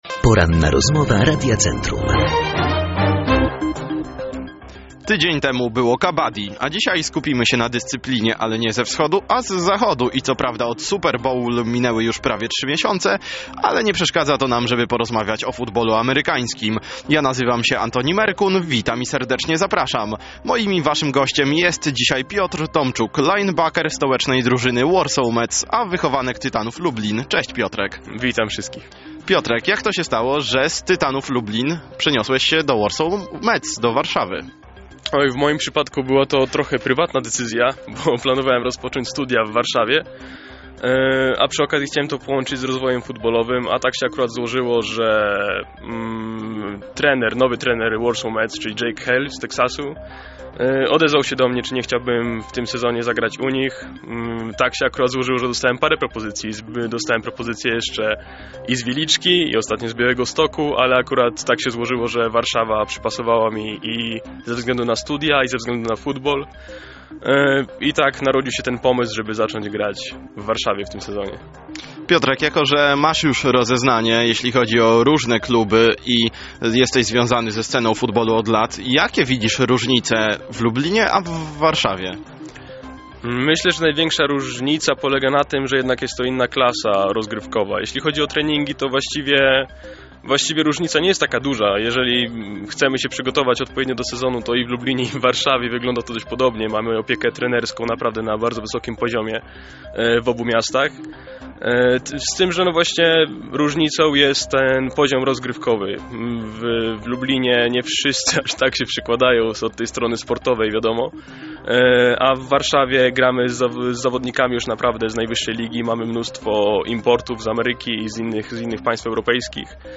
Opublikowano w Aktualności, Audycje, Poranna Rozmowa Radia Centrum, Sport